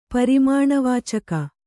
♪ parimāṇa vācaka